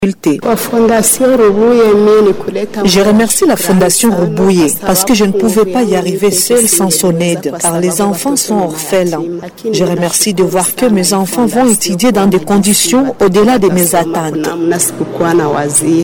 Les parents dont les enfants bénéficient de l’aide de la fondation Rubuye remercient, de leur côté, pour l’accompagnement qui permet à leurs enfants d’étudier sans beaucoup de difficultés. L’un d’eux en parle